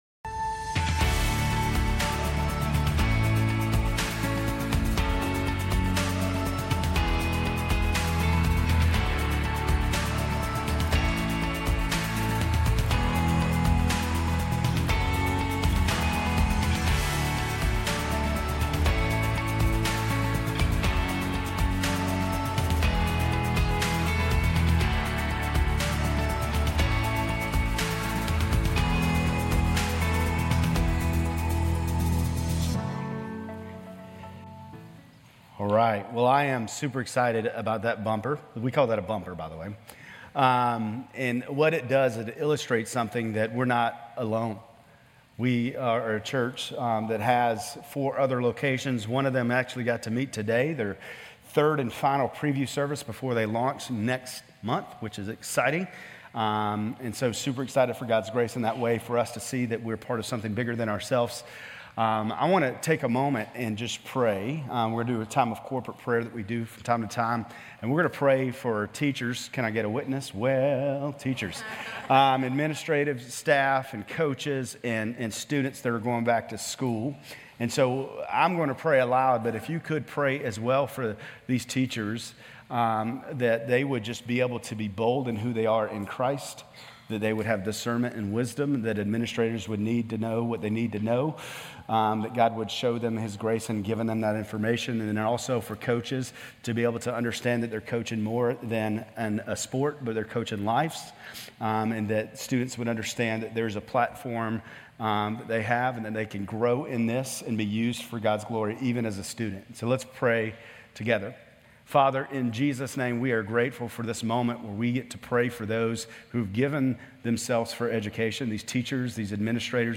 Grace Community Church Lindale Campus Sermons 8_11 Lindale Campus Aug 11 2024 | 00:30:37 1x 00:00 / 00:30:37 Subscribe Share RSS Feed Share Link Embed